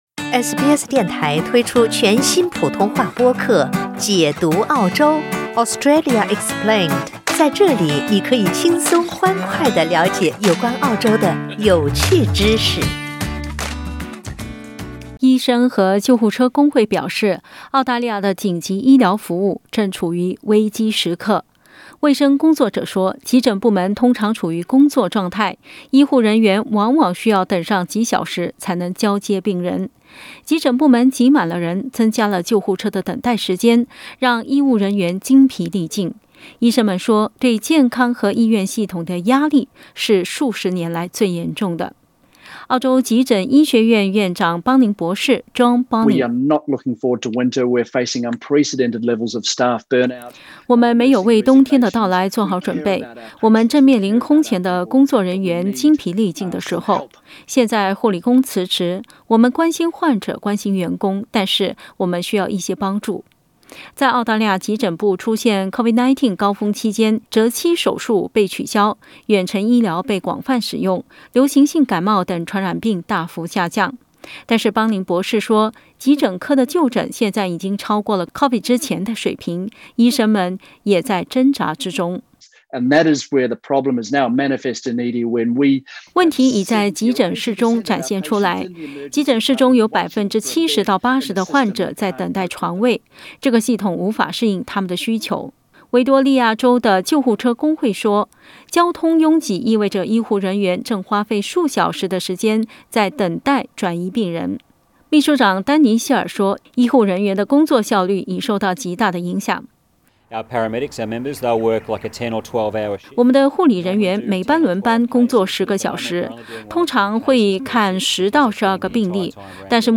（请听报道） 澳大利亚人必须与他人保持至少1.5米的社交距离，请查看您所在州或领地的最新社交限制措施。